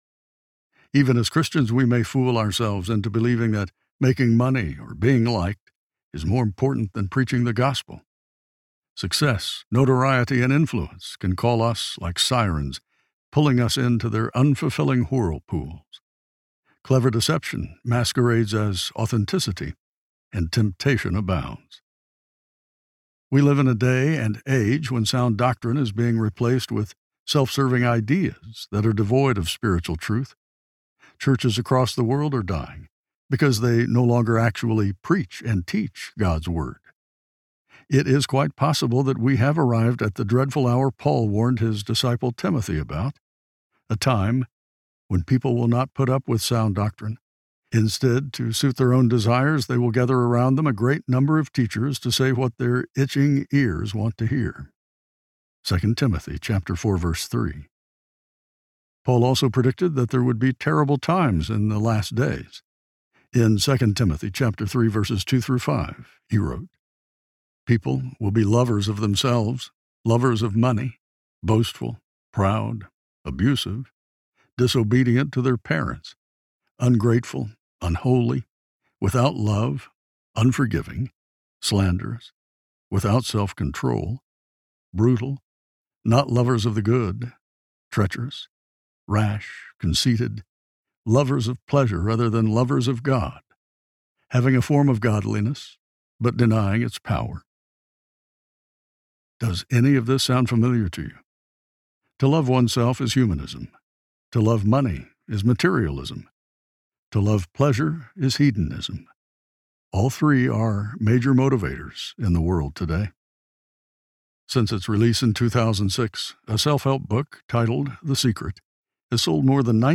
Compelled Audiobook
Narrator
6.52 Hrs. – Unabridged